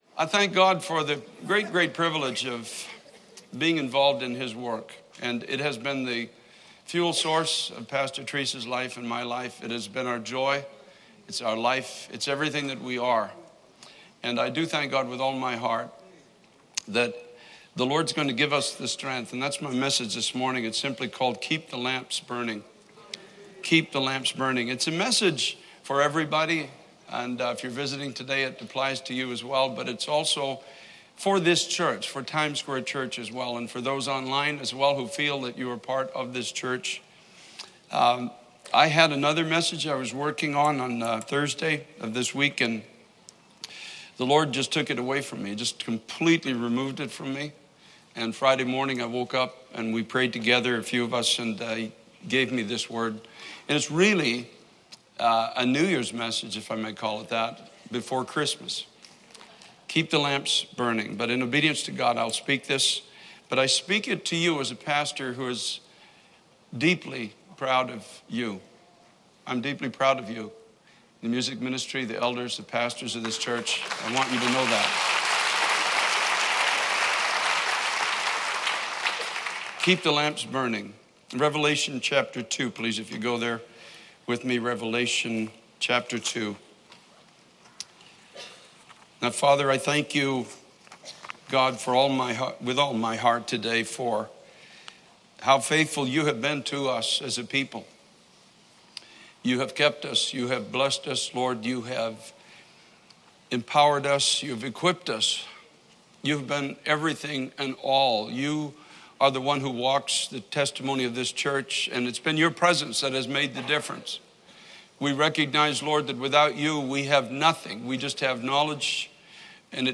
Keep the Lamps Burning | Times Square Church Sermons